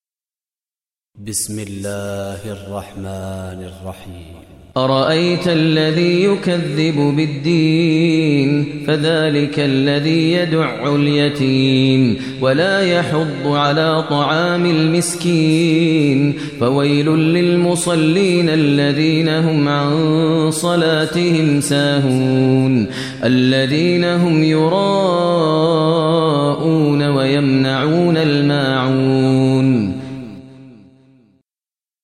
Surah Maun Recitation by Maher al Mueaqly
Surah Maun, listen online mp3 tilawat / recitation in Arabic recited by Imam e Kaaba Sheikh Maher al Mueaqly.